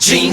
chicken.opus